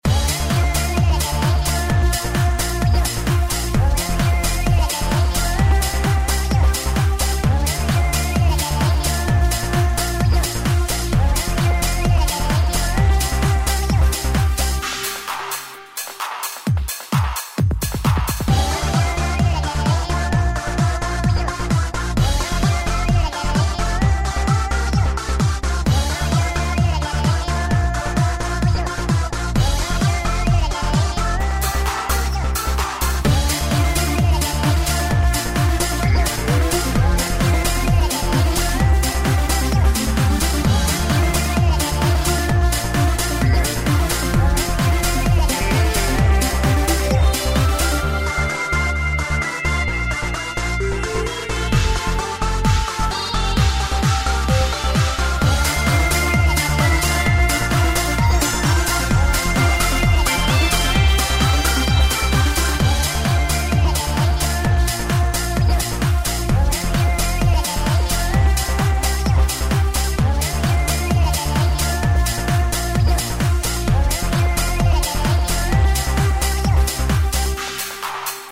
• Качество: 128, Stereo
Запоминающаяся мелодия из фильма